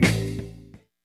Lo Fi Hit-A2.wav